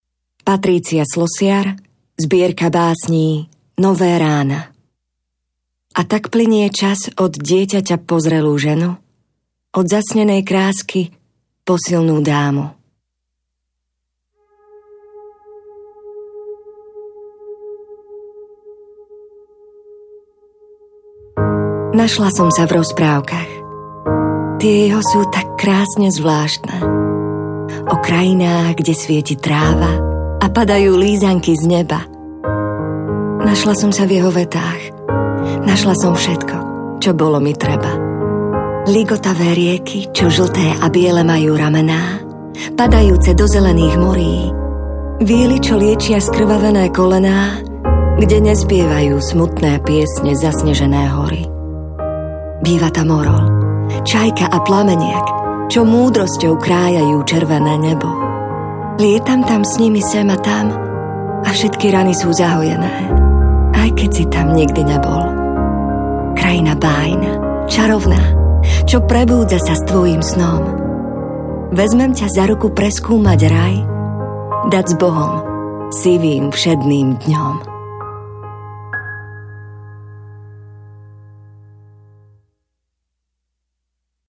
Nové rána audiokniha
Ukázka z knihy